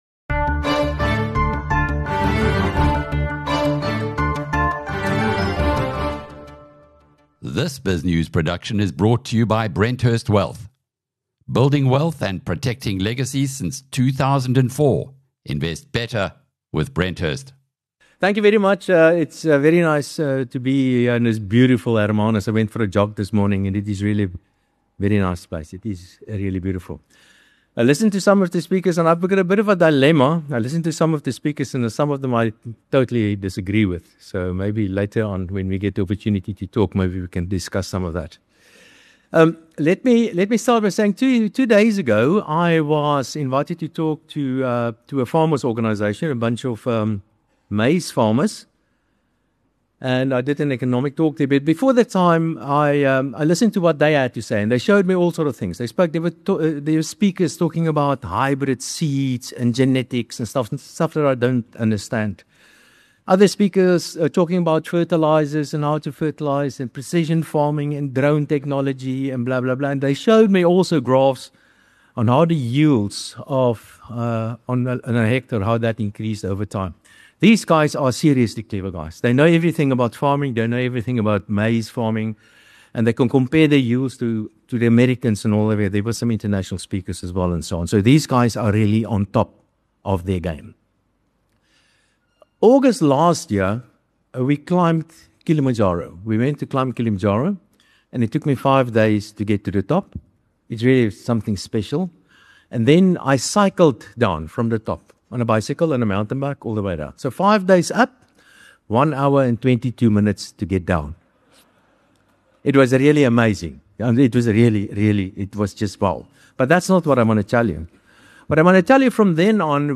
At BizNews Conference BNC#8 in Hermanus